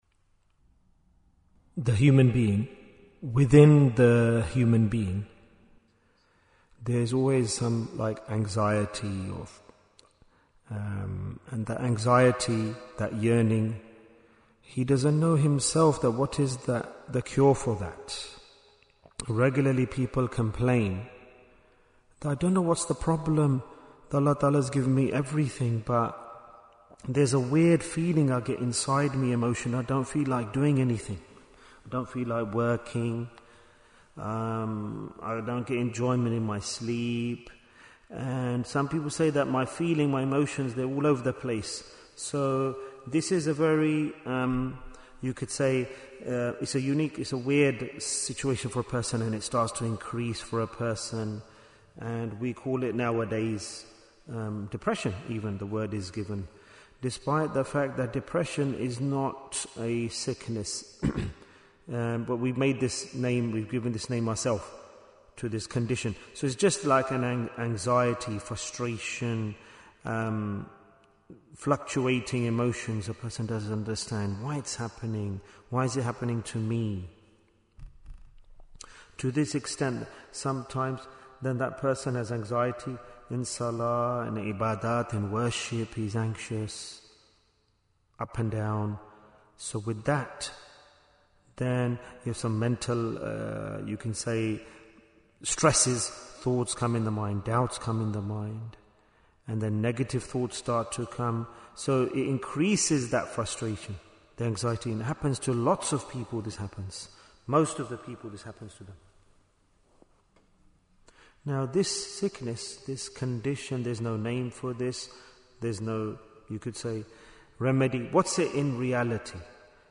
Why is Tazkiyyah Important? - Part 12 Bayan, 100 minutes24th January, 2026